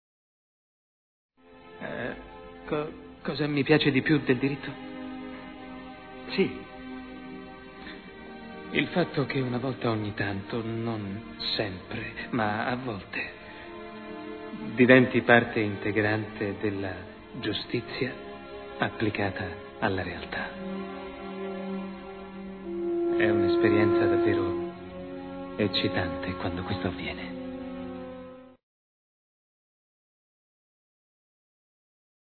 voce di Roberto Chevalier nel film "Philadelphia", in cui doppia Tom Hanks.